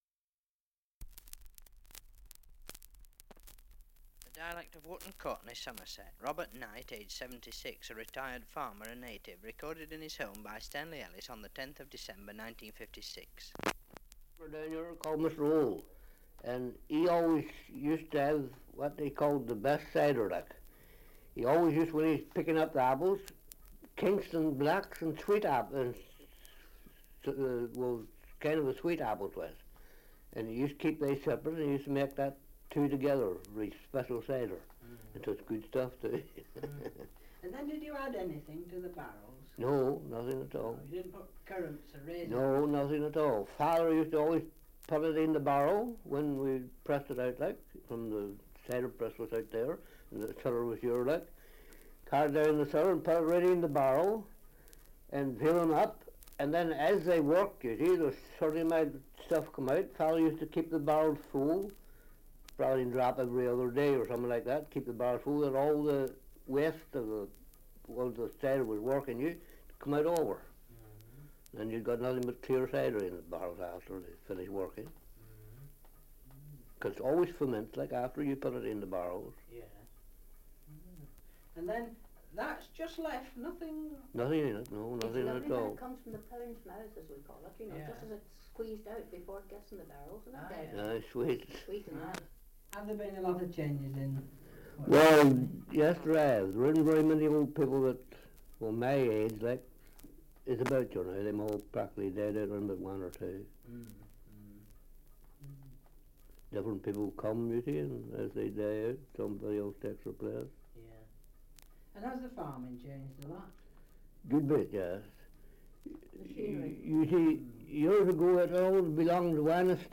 Survey of English Dialects recording in Wootton Courtenay, Somerset
78 r.p.m., cellulose nitrate on aluminium